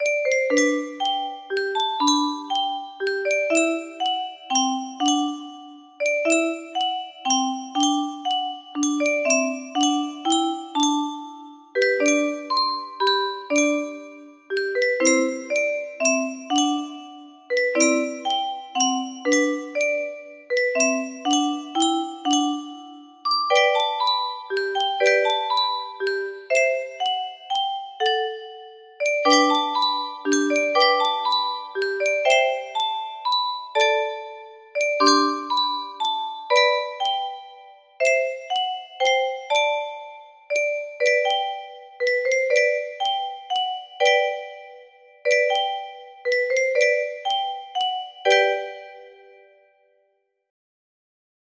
F Scale music box melody
Grand Illusions 30 (F scale)